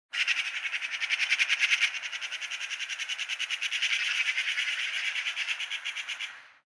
bird_chirp.ogg